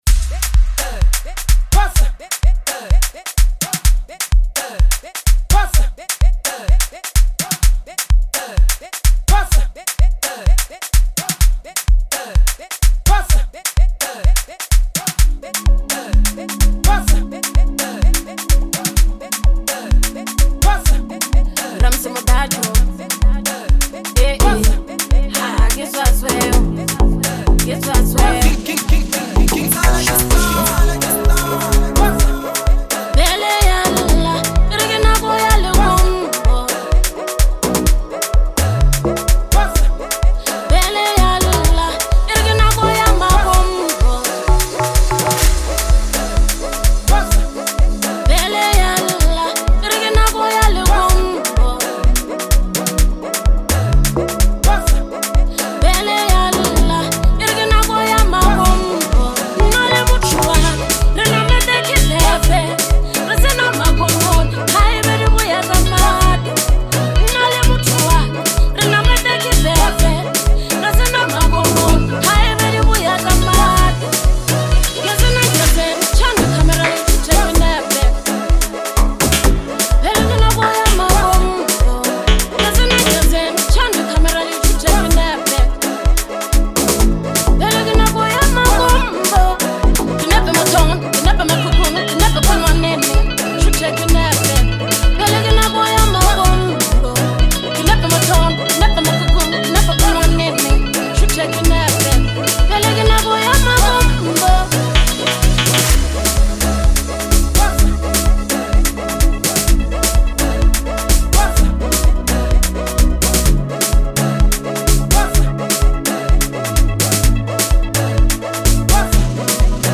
Lekompo/Bolo House anthem
heartfelt vocals
vibrant beat